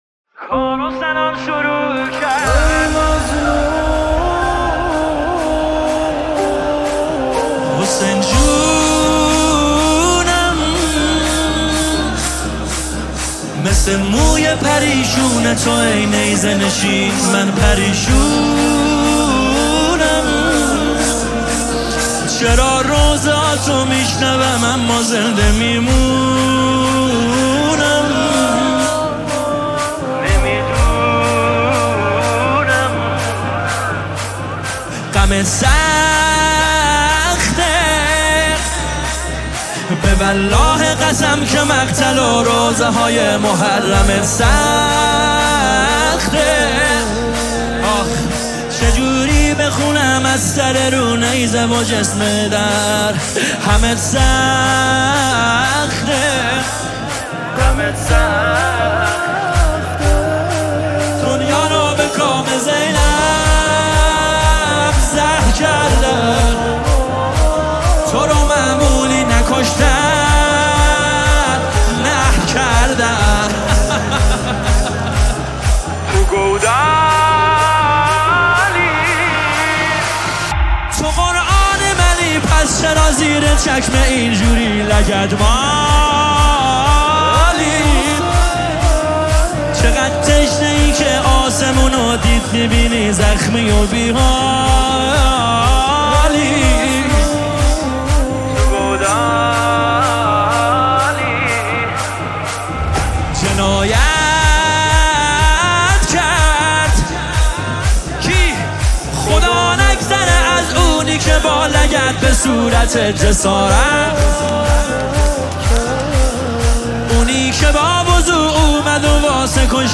دانلود نماهنگ دلنشین